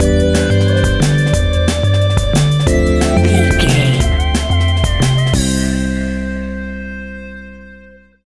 Ionian/Major
Fast
energetic
hypnotic
powerful
frantic
drum machine
synthesiser
electronic
sub bass